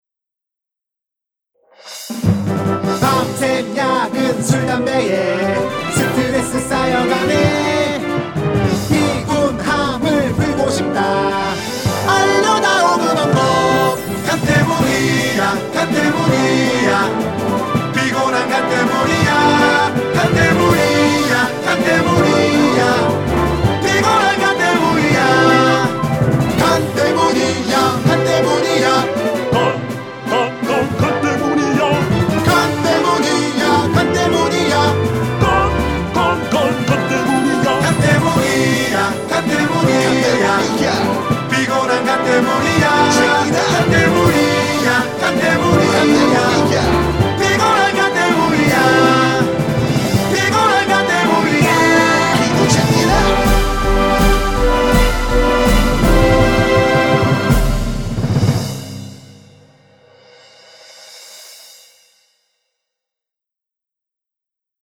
vocal 파일 있길래 받아서 대충 넣어본...수정> 원곡에 맞게 약간 빠르게 해 봄.